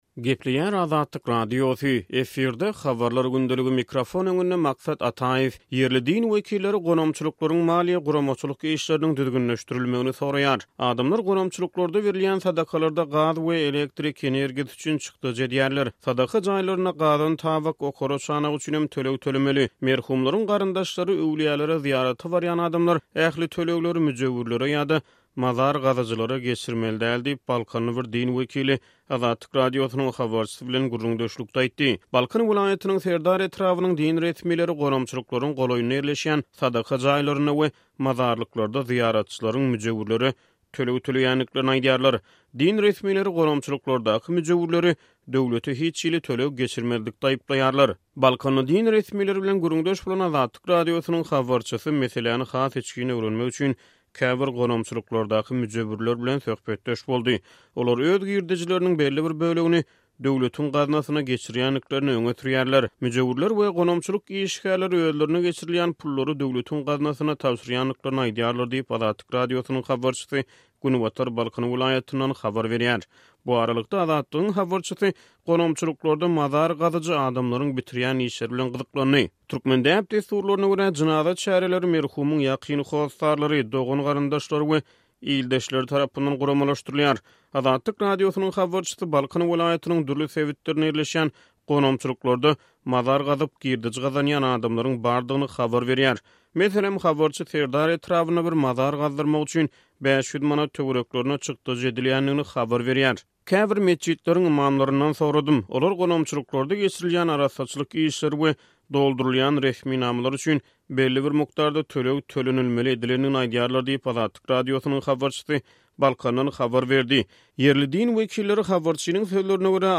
“Adamlar gonamçylyklarda berilýän sadakalarda gaz we elektrik energiýasy üçin çykdajy edýärler. Sadaka jaýlarynda gazan-tabak, okara-çanak üçin hem töleg tölemeli. Merhumlaryň garyndaşlary, öwülýälere zyýarat barýan adamlar ähli tölegleri müjewürlere ýa-da mazar gazyjylara geçirmeli däl” diýip, balkanly bir din wekili Azatlyk Radiosynyň habarçysy bilen gürrüňdeşlikde aýtdy.